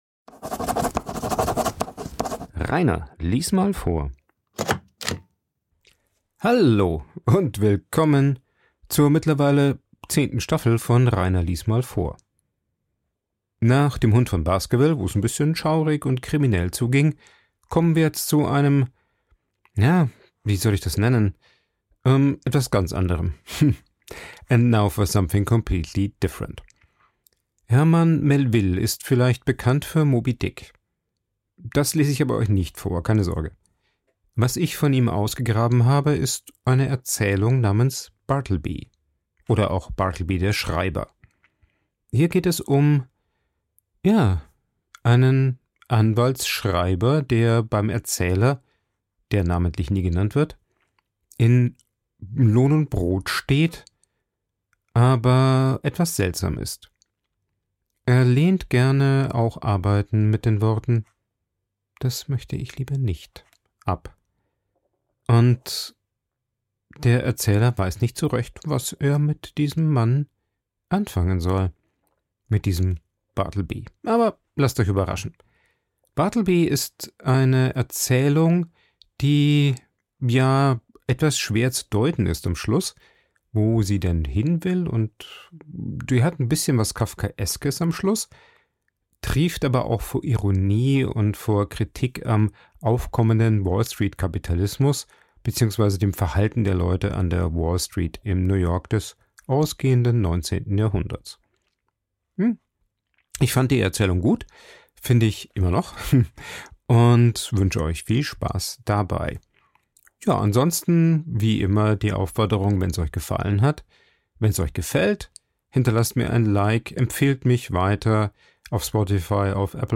Ein Vorlese Podcast